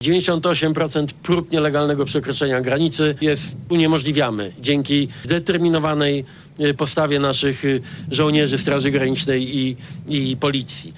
W sumie naszej wschodniej granicy pilnuje 11 tysięcy funkcjonariuszy – mówił premier Donald Tusk